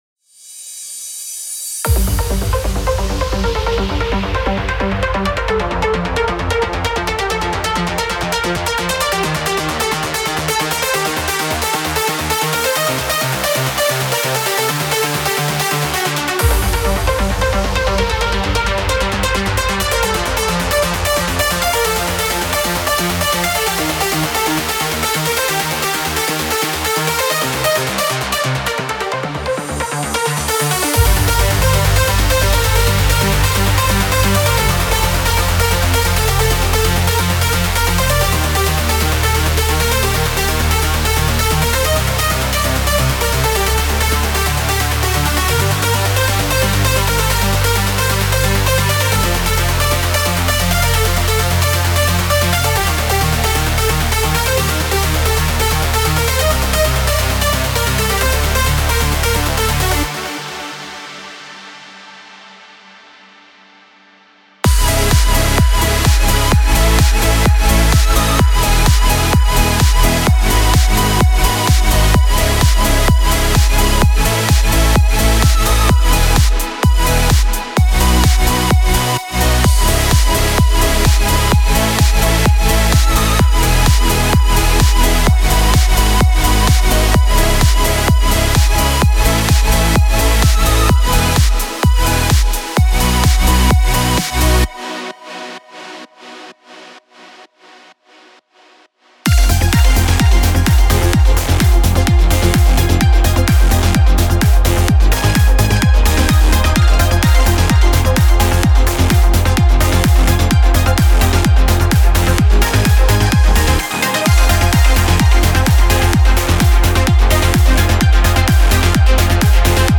Genre: Trance
Demo Mix Down of Each Kit.